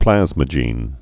(plăzmə-jēn)